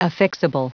Prononciation du mot affixable en anglais (fichier audio)
Prononciation du mot : affixable